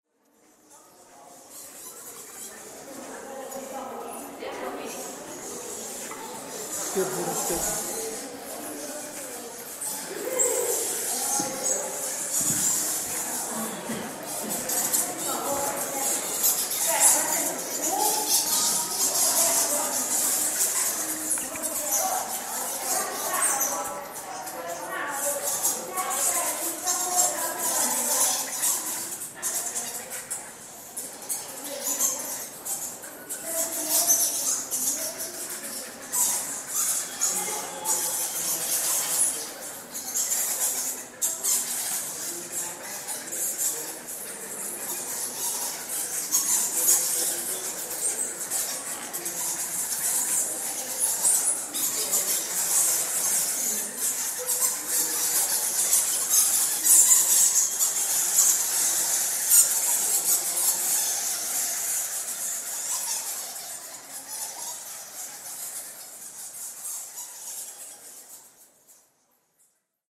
Bats in the temple, Myanmar